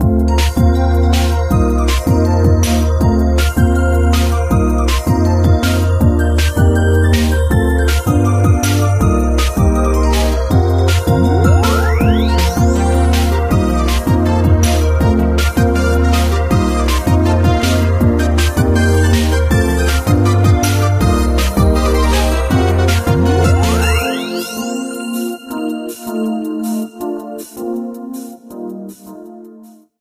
Alternate background music